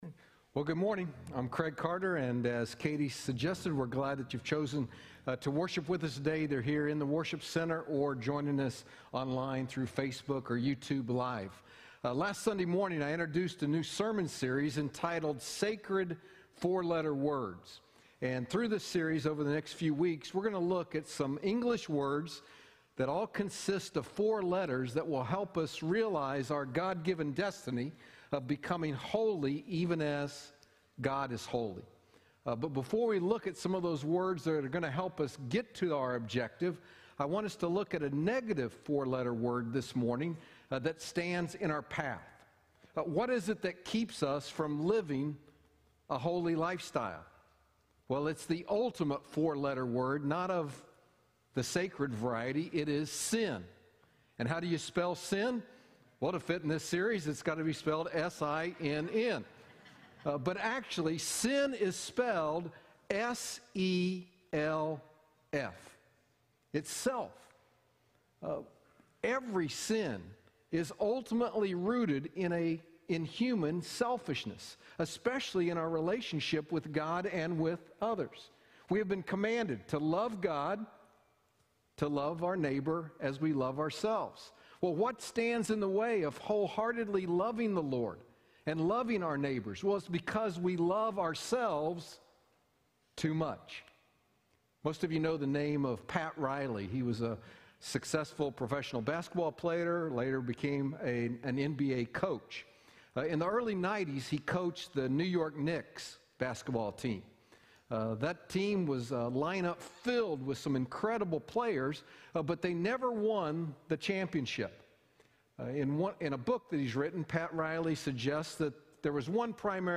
Sacred 4-Letter Words Service Type: Sunday Morning Download Files Notes Bulletin « Sacred 4-Letter Words
Sermon-audio-1.17.21.mp3